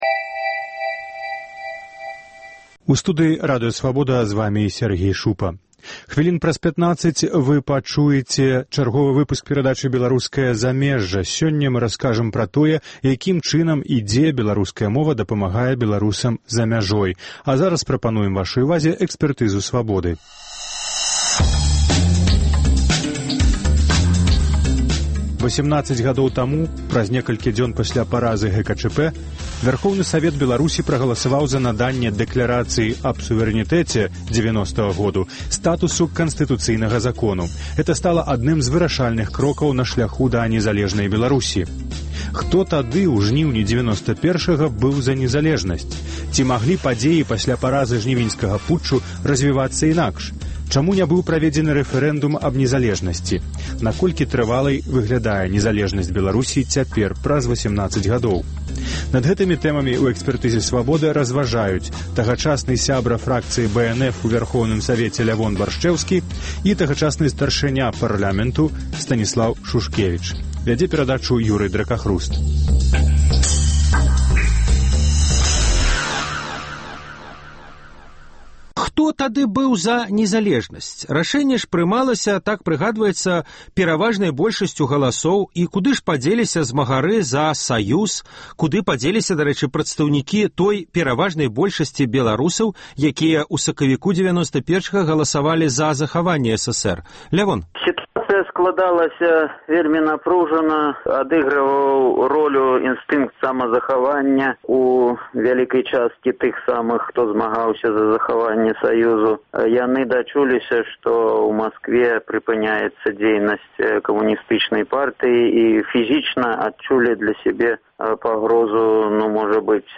Наколькі трывалай выглядае незалежнасьць Беларусі зараз, праз 18 гадоў? Над гэтымі тэмамі разважаюць: тагачасны сябра фракцыі БНФ у Вярхоўным Савеце Лявон Баршчэўскі і тагачасны старшыня парлямэнту Станіслаў Шушкевіч.